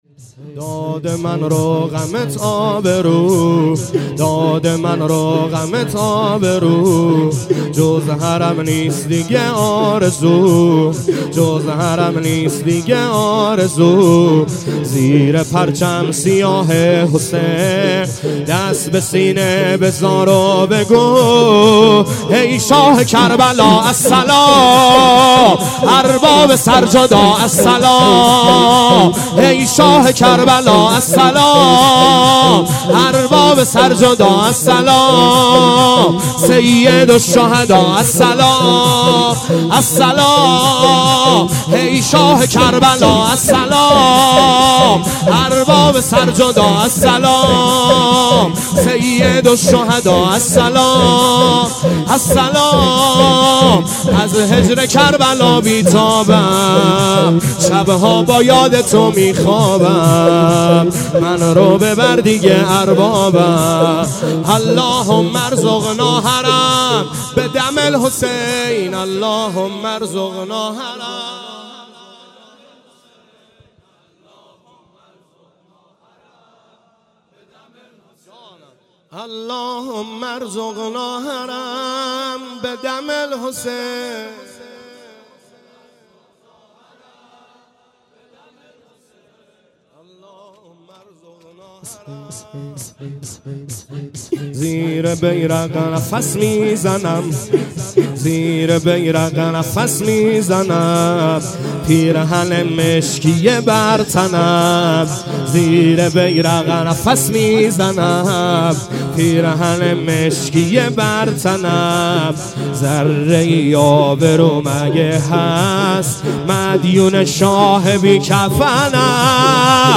شور ( ای شاه کربلا السلام
مراســم عـزادارى شـب سوم محرّم
محرم 97 - شب سوم